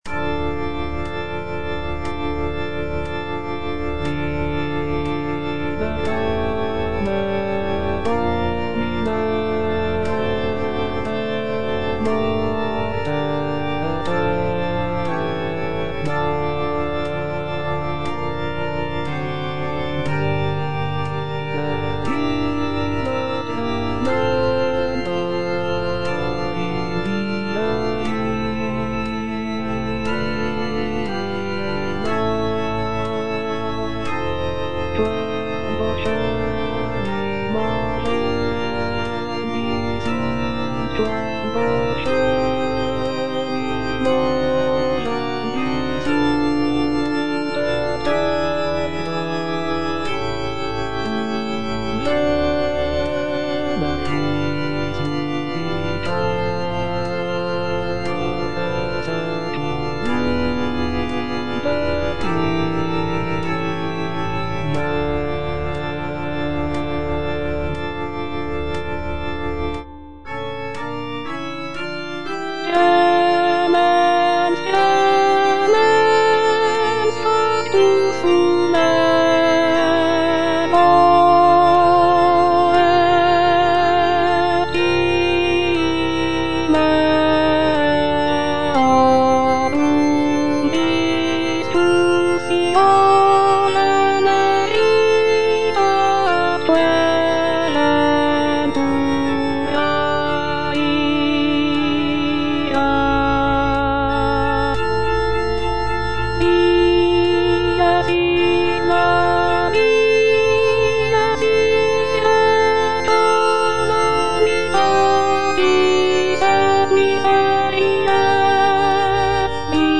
G. FAURÉ - REQUIEM OP.48 (VERSION WITH A SMALLER ORCHESTRA) Libera me - Alto (Voice with metronome) Ads stop: